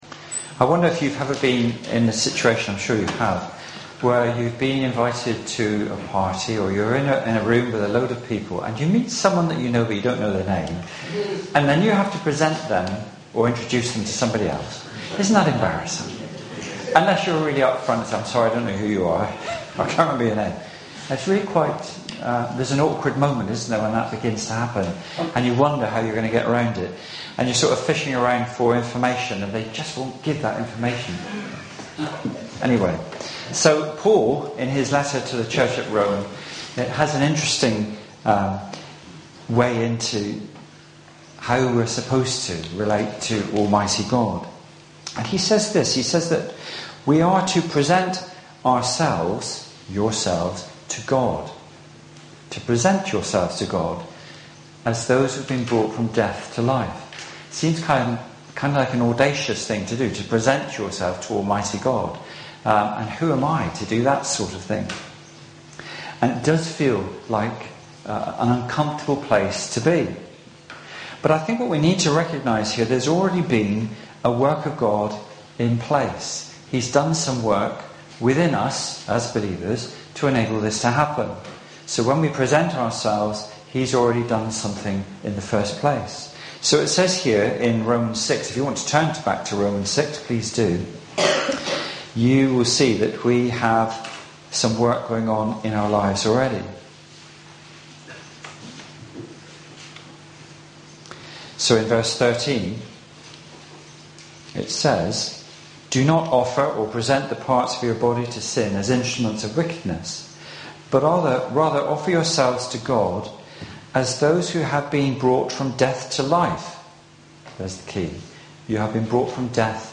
Sermon-2-July-2017.mp3